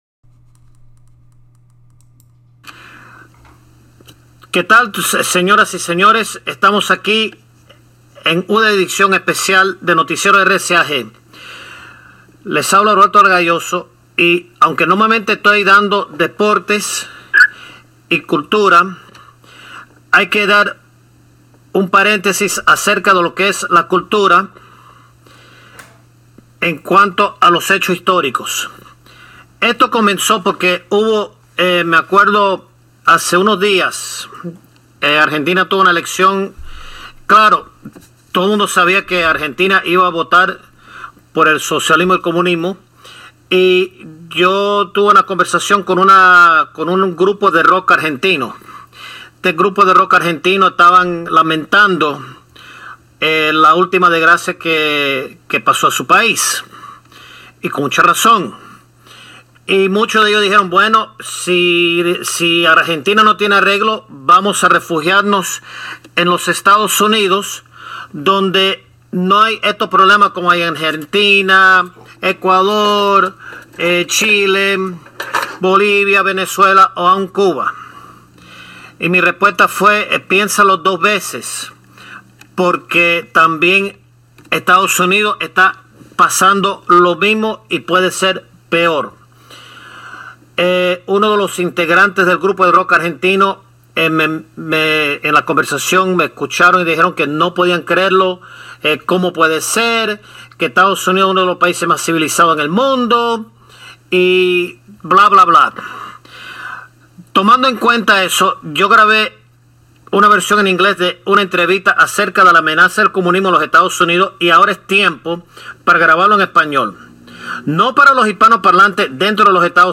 ENTREVISTADO POR EL NOTICIERO RCAG